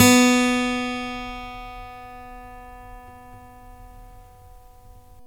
Index of /90_sSampleCDs/Roland L-CD701/GTR_Steel String/GTR_ 6 String
GTR 6STR B06.wav